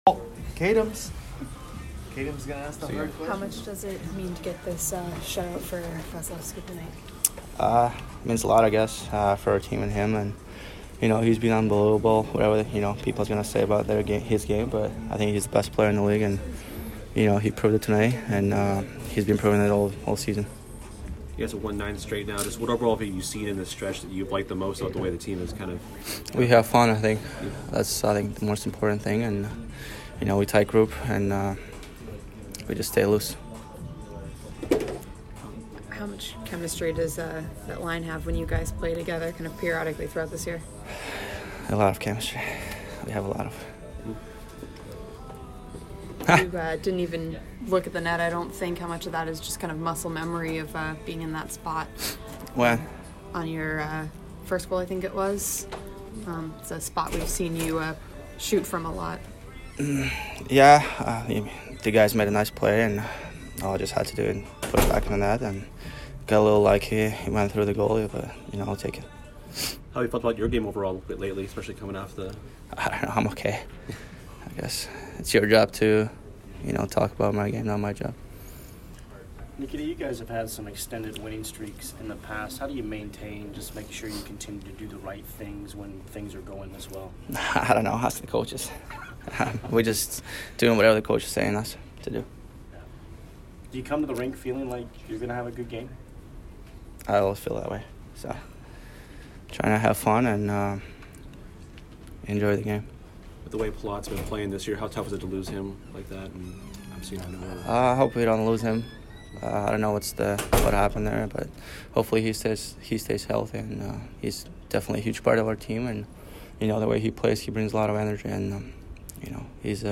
Kucherov post-game 1/9